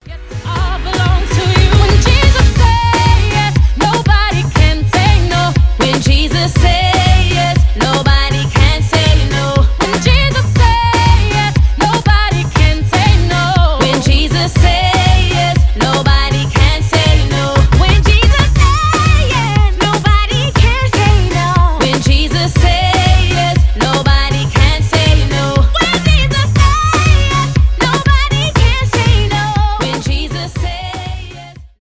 поп
госпел
rnb , afrobeat